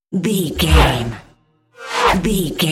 Whoosh electronic metal fast
Sound Effects
Fast
futuristic
intense